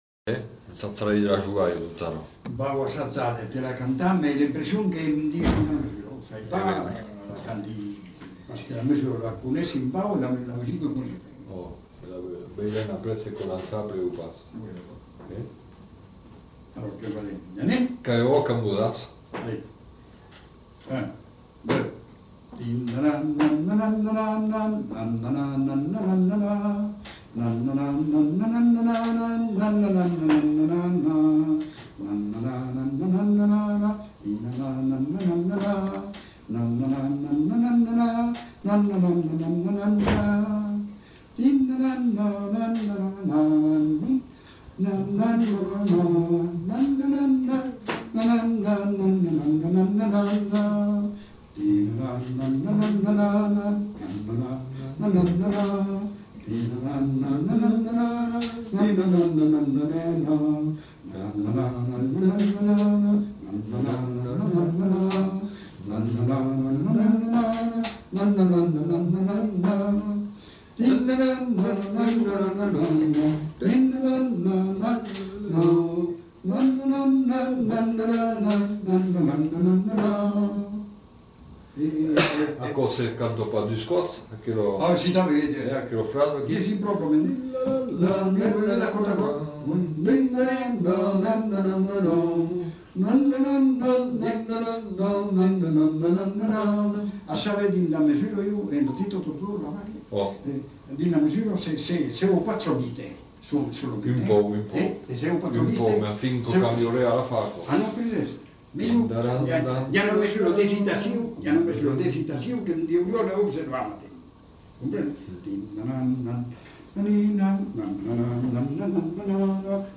Aire culturelle : Haut-Agenais
Genre : chant
Effectif : 2
Type de voix : voix d'homme
Production du son : fredonné
Danse : mazurka
L'enquêteur accompagne l'interprète.